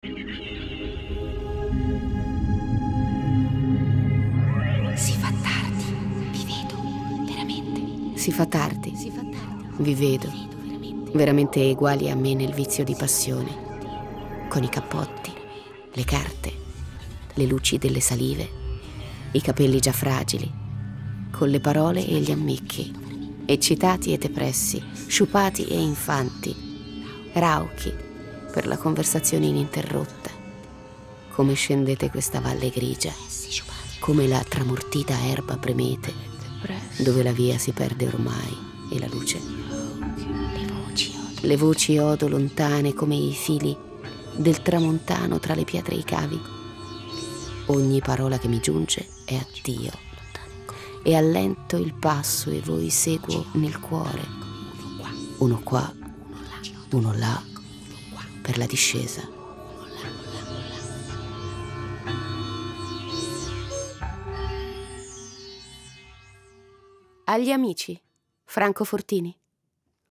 Abbiamo immaginato un contenitore dove si possano ascoltare delle prime letture poetiche. Sessanta autori per sessanta poesie.